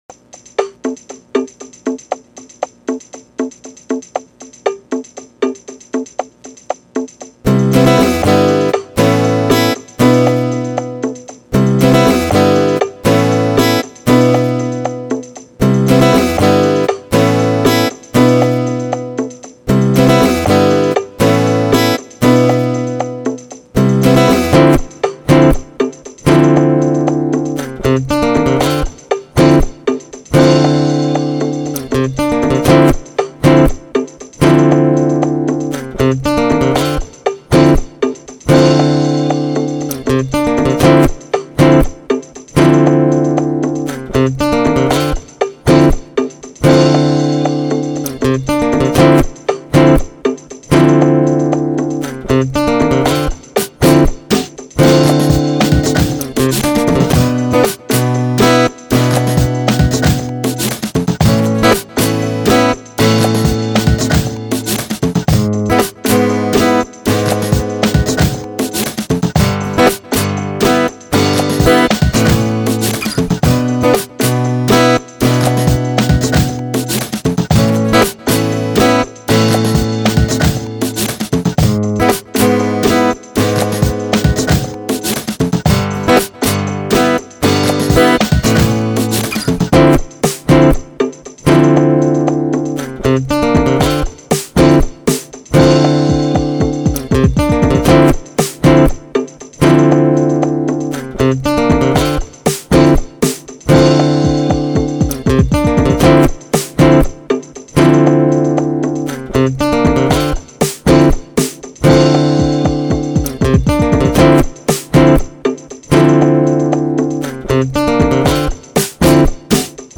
guitar
117.79bpm